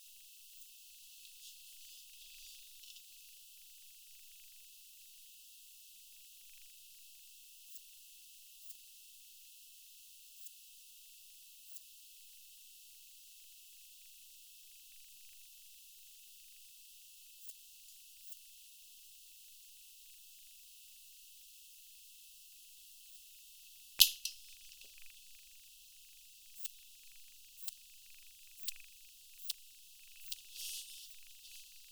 Data resource Xeno-canto - Orthoptera sounds from around the world